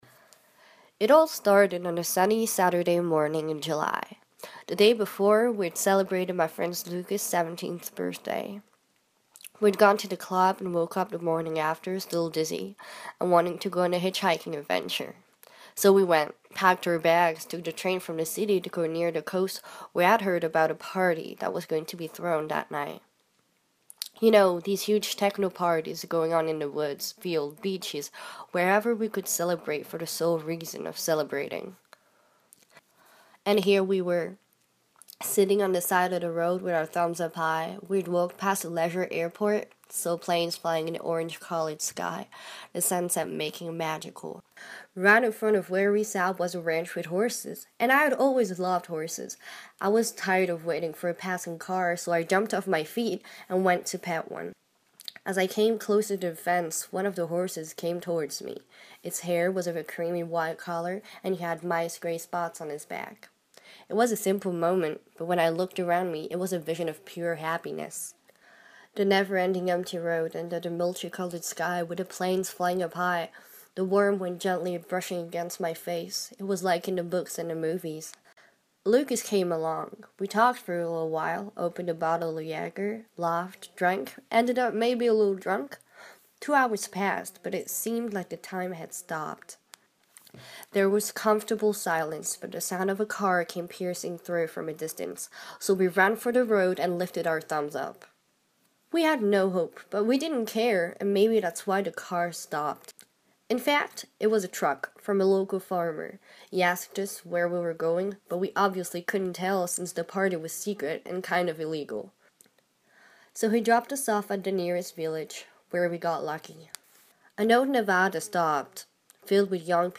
Lecture d'une nouvelle en anglais